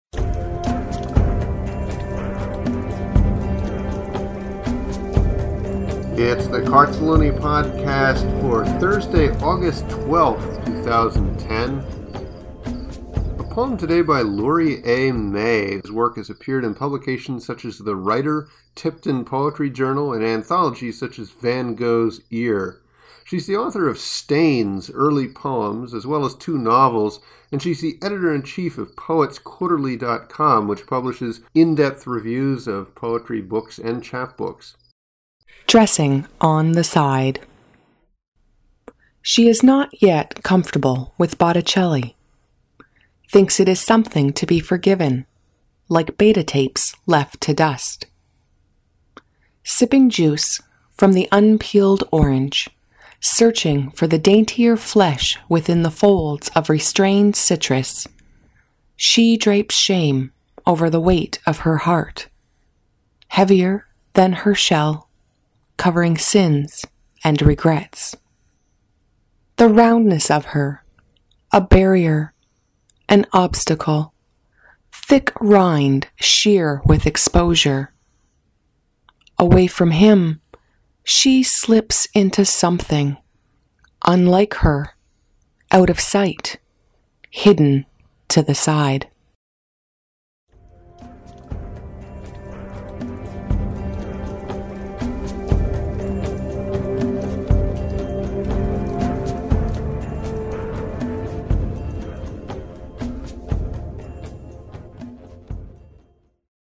Lovely poem, lovely voice!